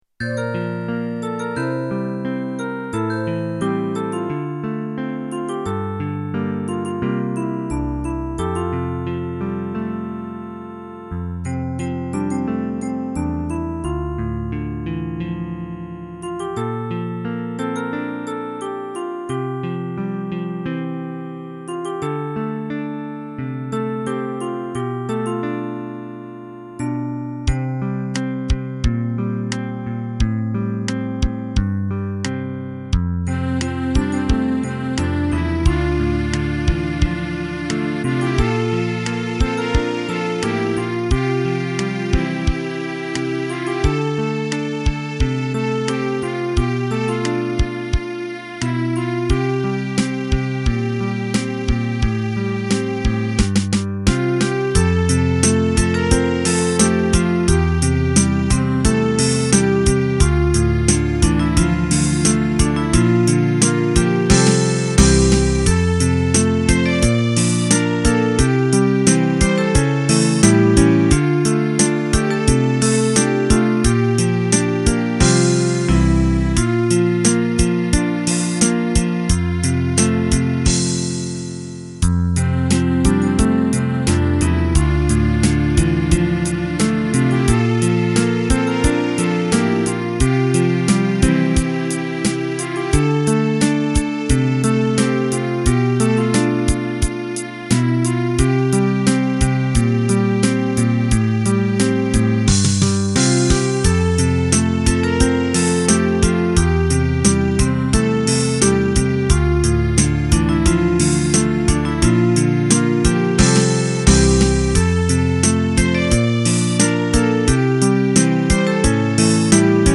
DTM